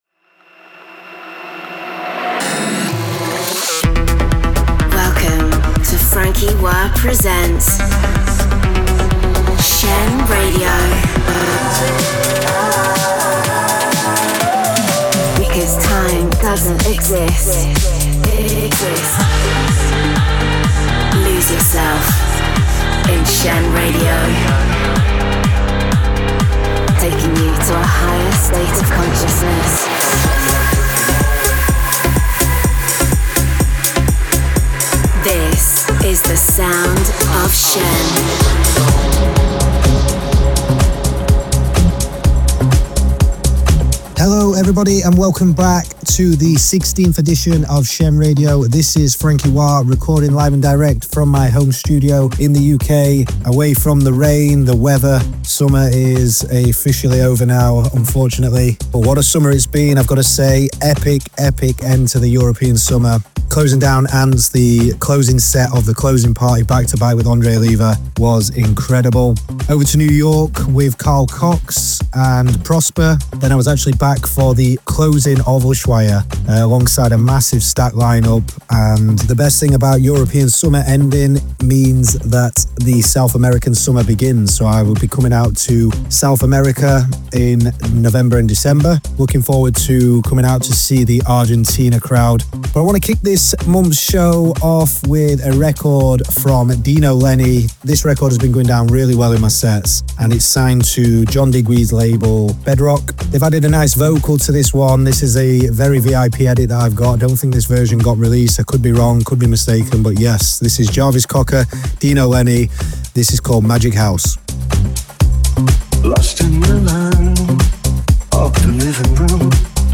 conceptual, cutting-edge, progressive sounds